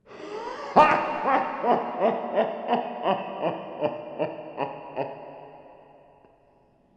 laugh
abominable alarming appalling beast beelzebuub bogey chuckle cinematic sound effect free sound royalty free Funny